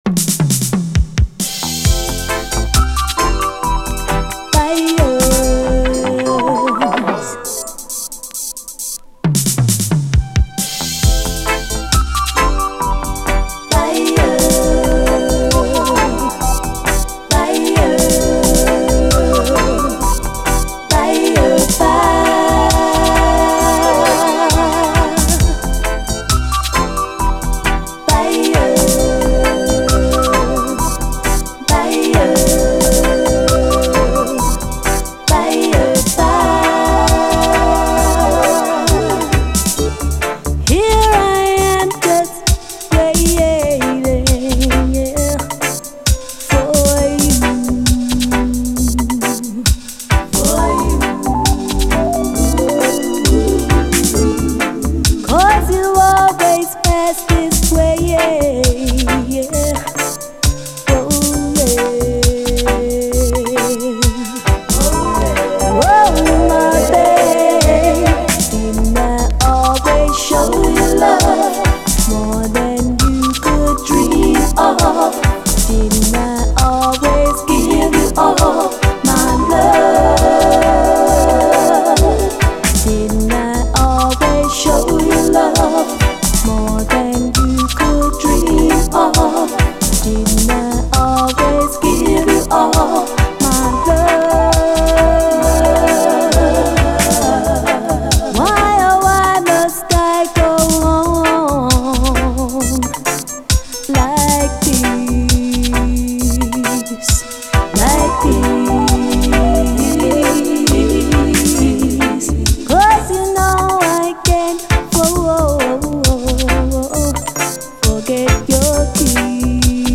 REGGAE
試聴ファイルはこの盤からの録音です
後半のダブも最高です。